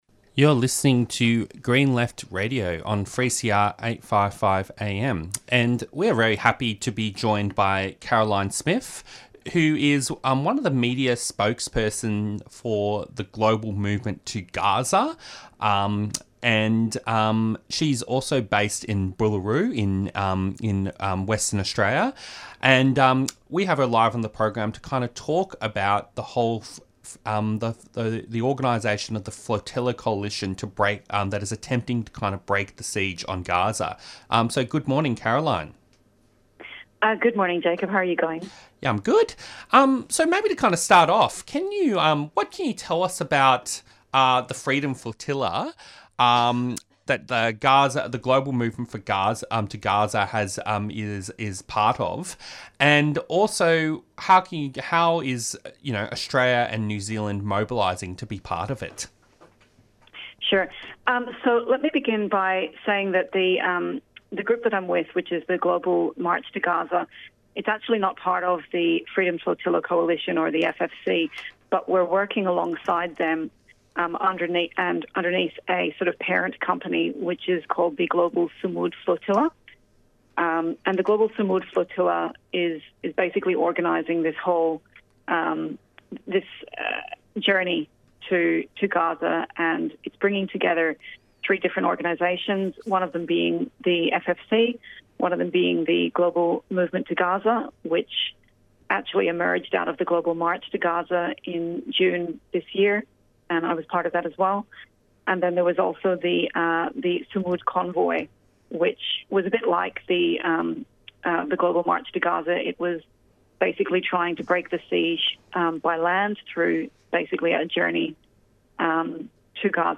Interviews and Discussions